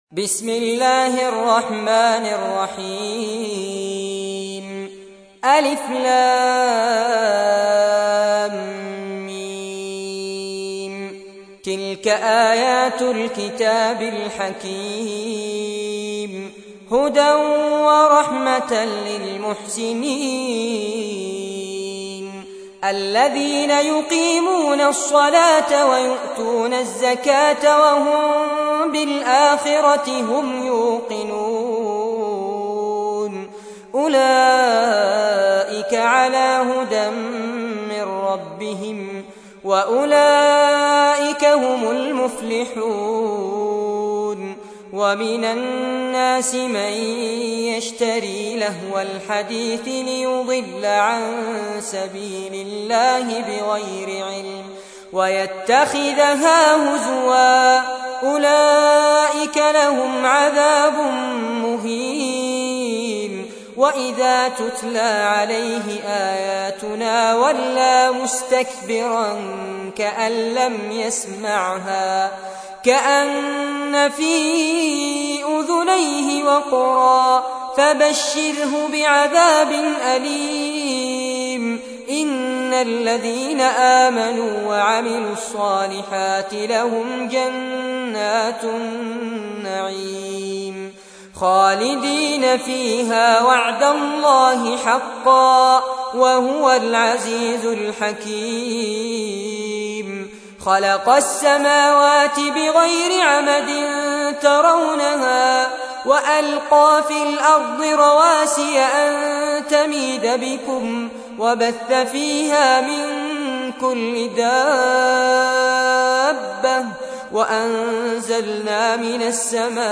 تحميل : 31. سورة لقمان / القارئ فارس عباد / القرآن الكريم / موقع يا حسين